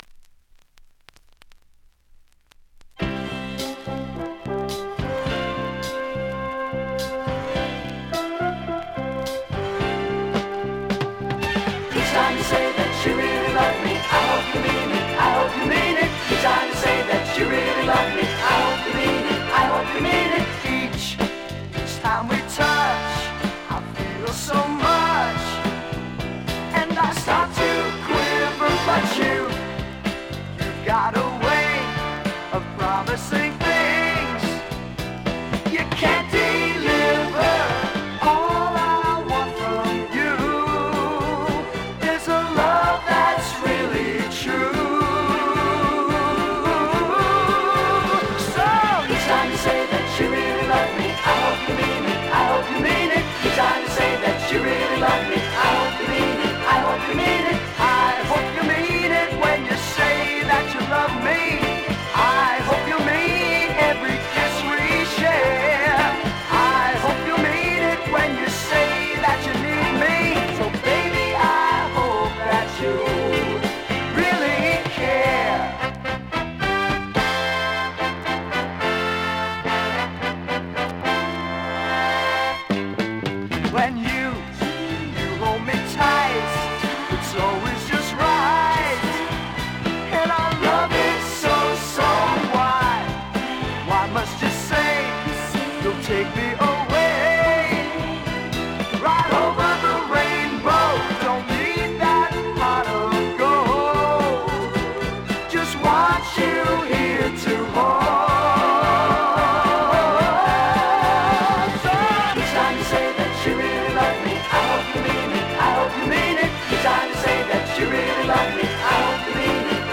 ◆USA盤 オリジナル 7"Single 45 RPM現物の試聴（両面すべて録音時間5分）できます。